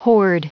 Prononciation du mot horde en anglais (fichier audio)
Prononciation du mot : horde